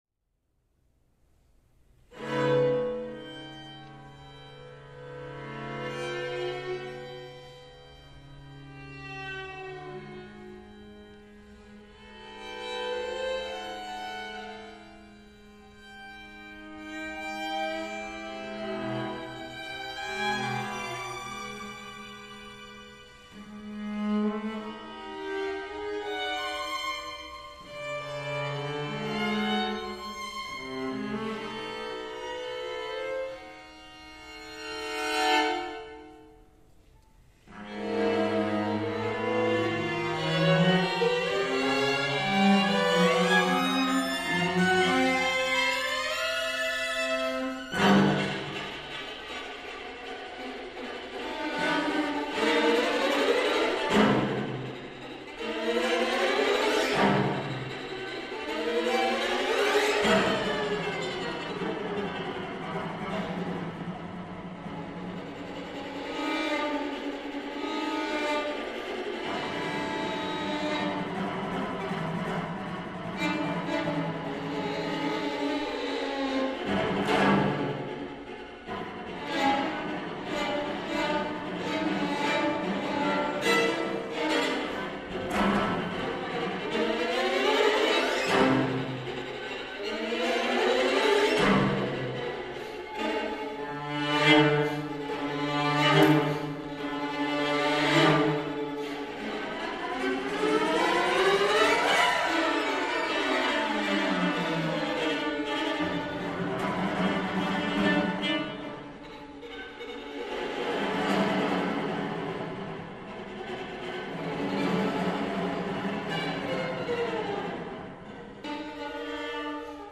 ‘Brinner’ World Premiere. Palladium Theatre 19th November 2014